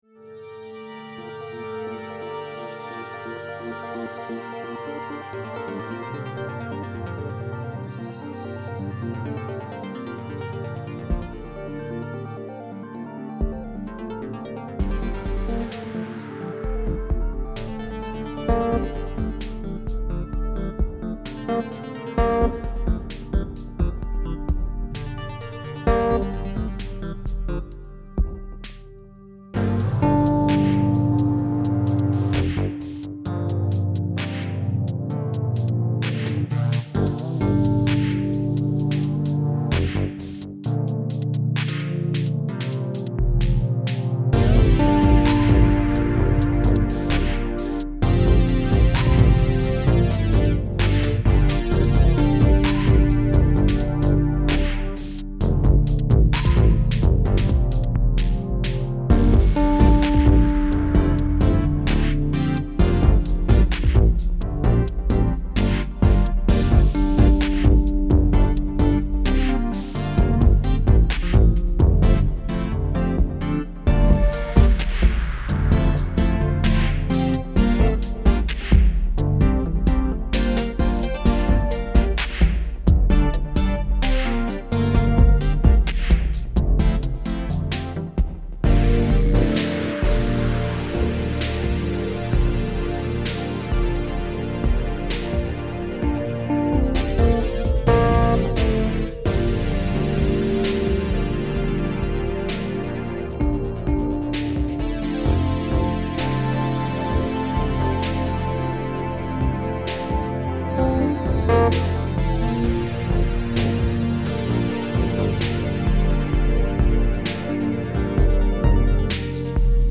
These original tracks have been created using real instruments, midi composition, and recorded sound.